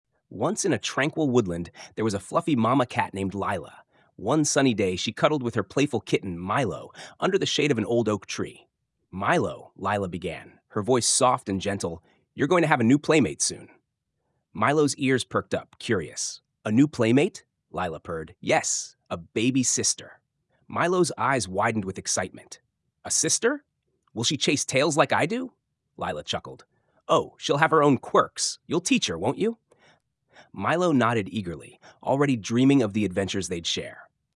Av röstexemplen i OpenAI:s tillkännagivande att döma har företaget fått till en kompetent text-till-tal-motor (i alla fall på engelska), både med kvinnliga och manliga röster.
OpenAI har samarbetat med professionella röstskådespelare för att skapa rösterna och använder egna systemet Whisper för taligenkänning.